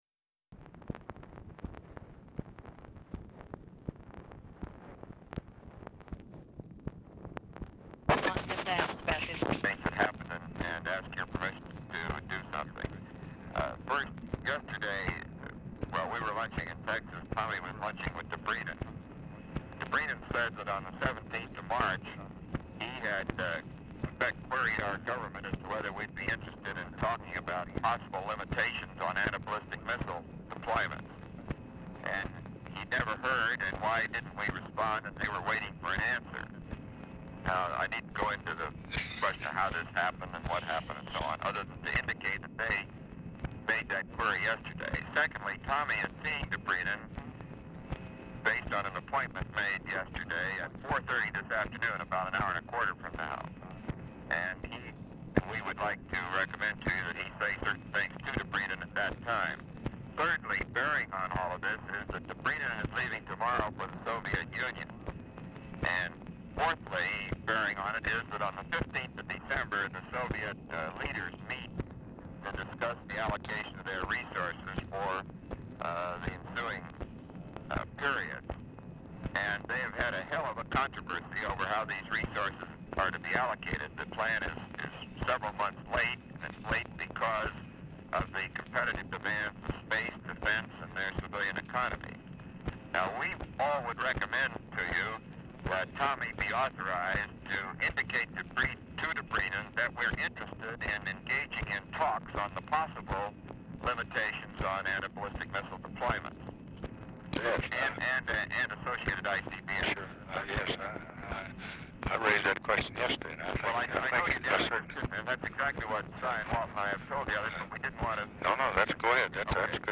RECORDING STARTS AFTER CONVERSATION HAS BEGUN
Format Dictation belt
Location Of Speaker 1 LBJ Ranch, near Stonewall, Texas
Specific Item Type Telephone conversation